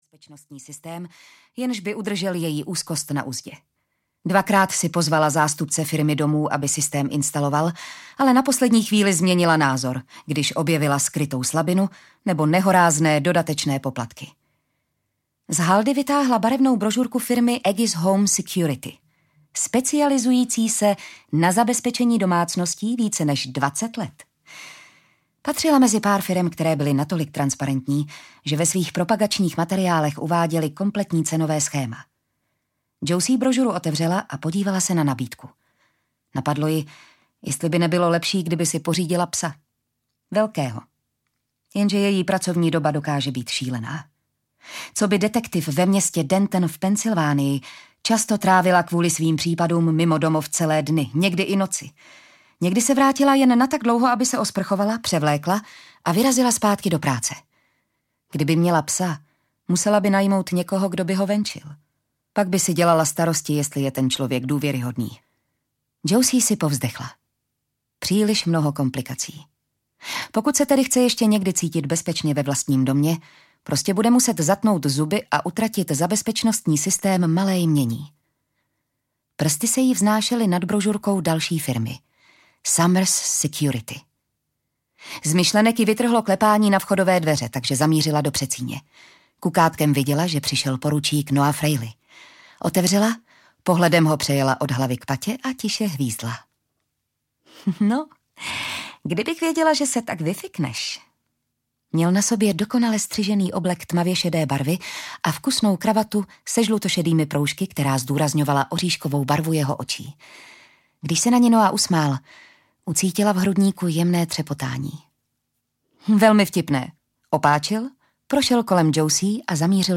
Nečekané přiznání audiokniha
Ukázka z knihy